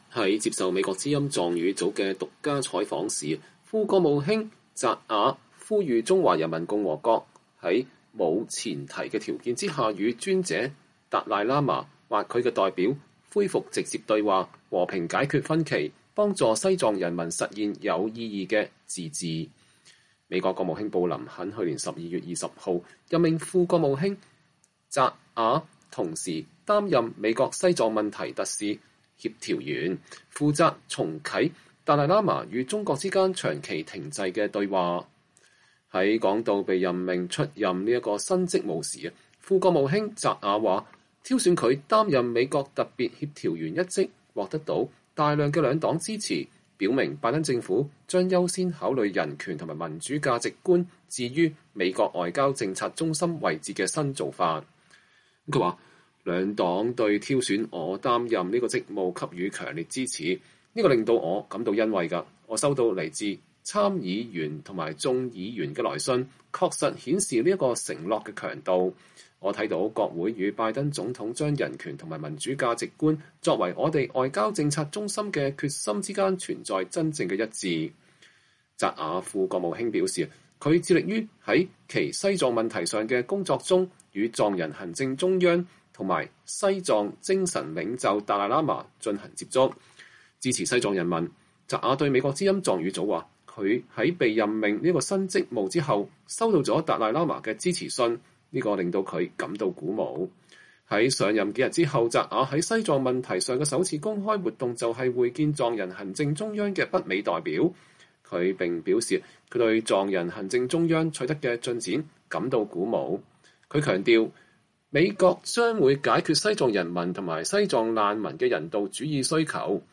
VOA專訪美西藏事務特別協調員：拜登政府致力於西藏事務